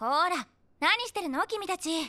sukasuka-anime-vocal-dataset